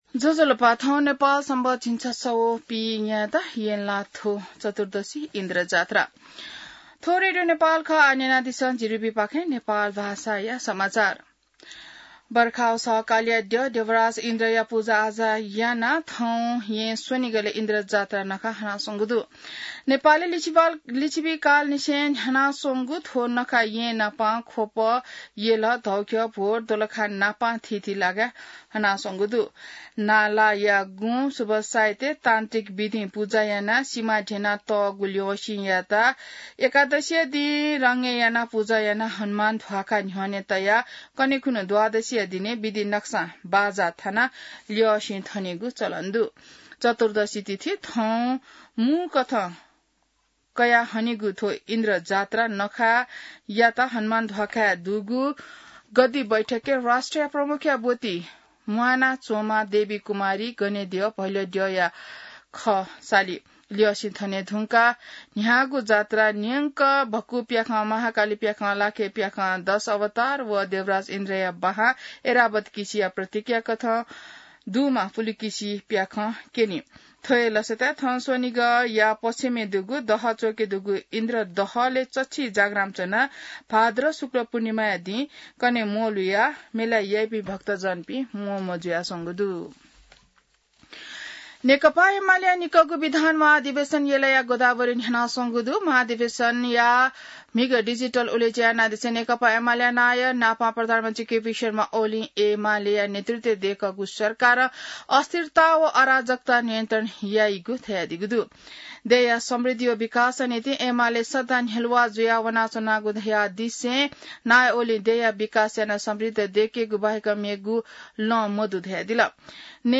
नेपाल भाषामा समाचार : २१ भदौ , २०८२